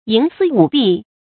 注音：ㄧㄥˊ ㄙㄧ ㄨˇ ㄅㄧˋ
營私舞弊的讀法